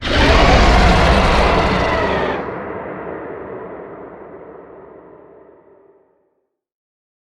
Sfx_creature_squidshark_callout_05.ogg